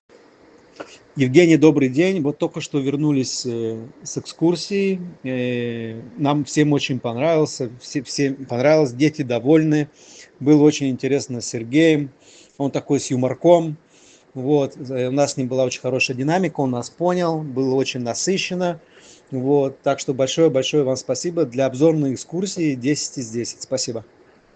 Аудио-отзыв